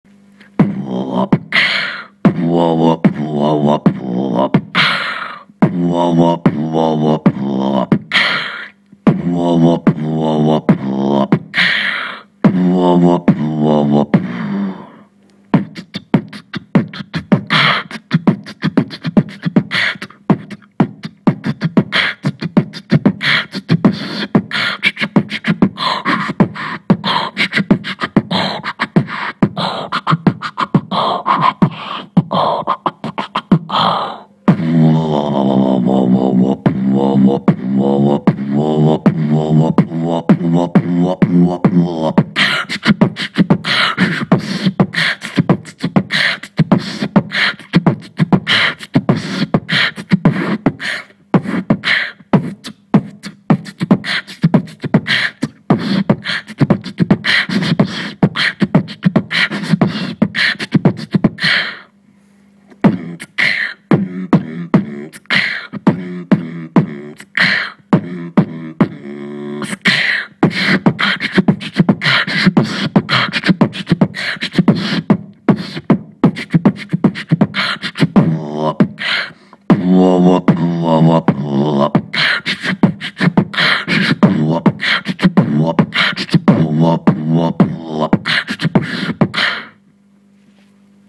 Форум российского битбокс портала » Реорганизация форума - РЕСТАВРАЦИЯ » Выкладываем видео / аудио с битбоксом » Оцените (Строго не судите записовал на диктофон))
клеп и горловой бас точи